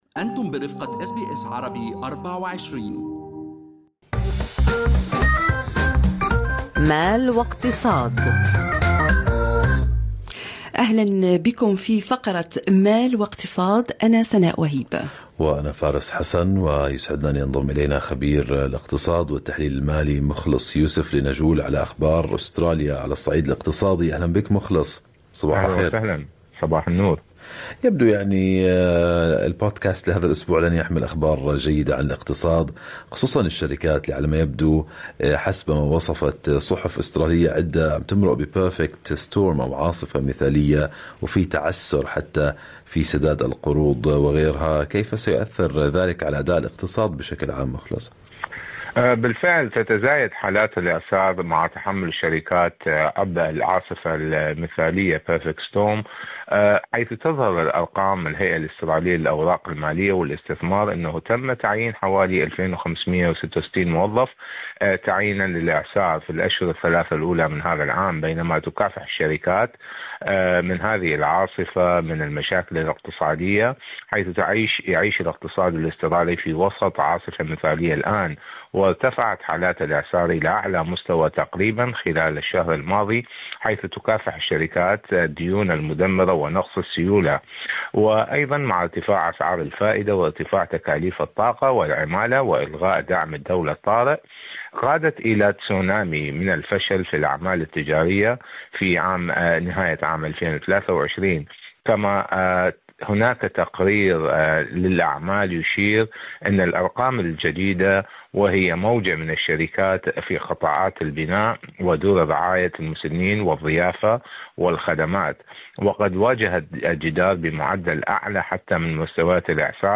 استمعوا إلى المقابلة الكاملة مع الخبير الاقتصادي من برنامج Good Morning Australia من خلال الرابط أعلاه.